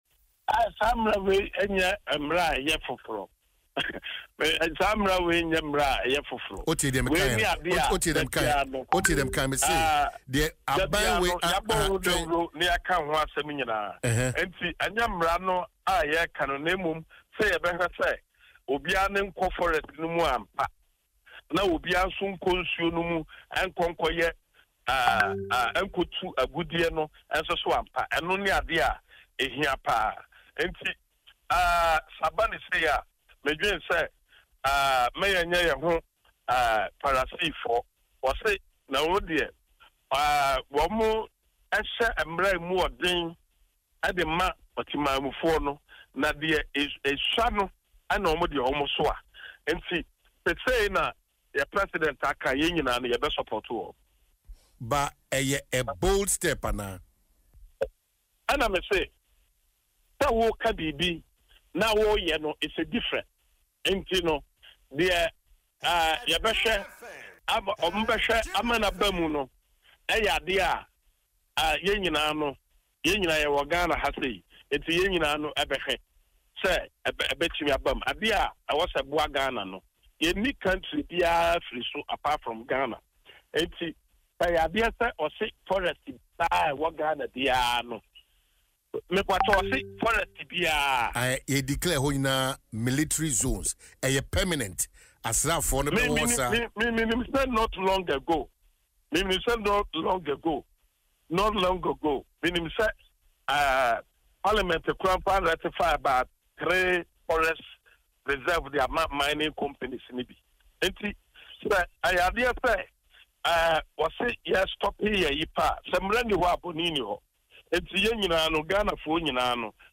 Speaking on Adom FM’s morning show Dwaso Nsem, Wontumi said they just have to ensure that people don’t go and engage in illegal mining.